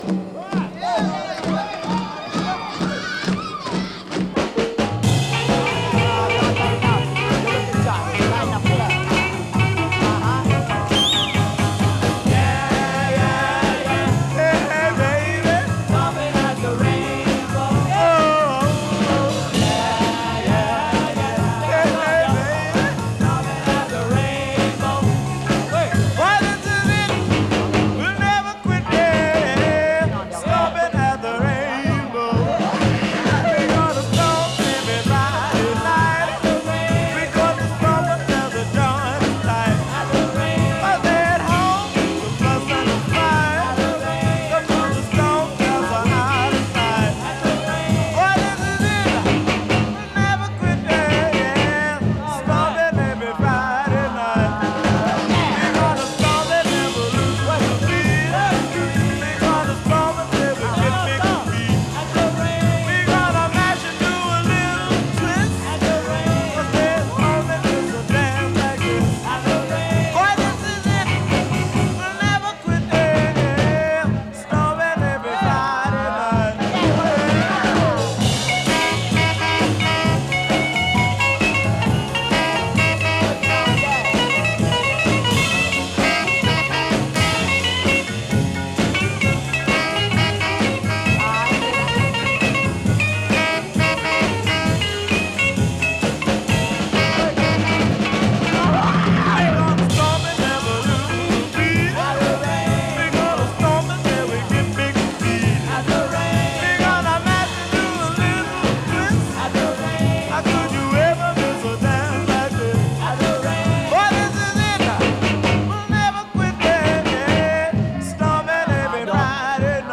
live LP
mostly instrumental tracks with a Southern California flair